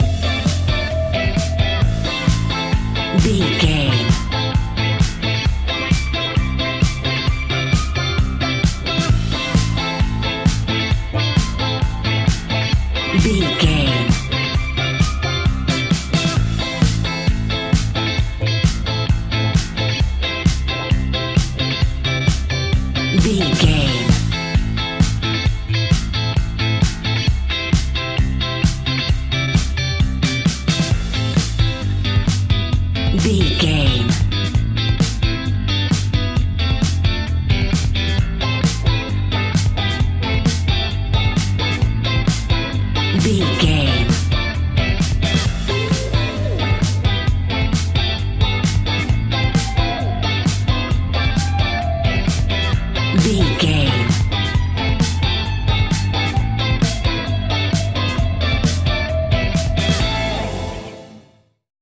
Ionian/Major
A♭
house
synths
techno
trance